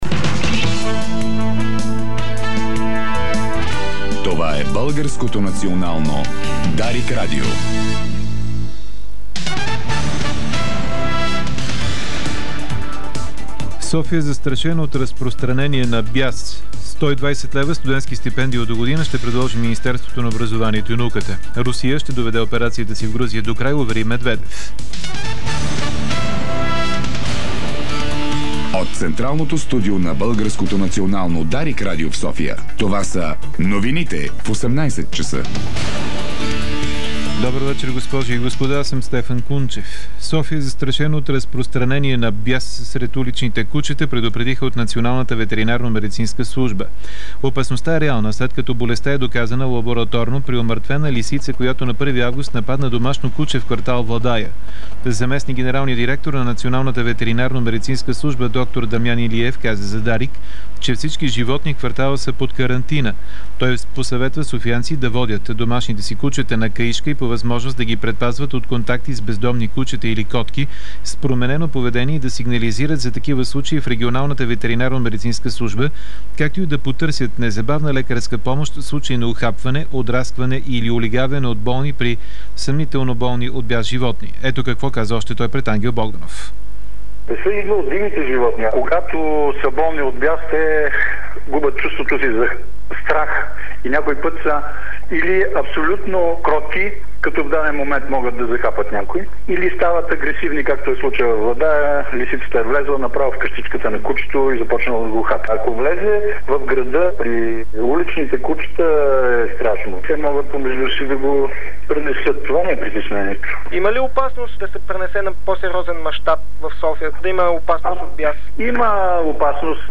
Обзорна информационна емисия - 11.08.2008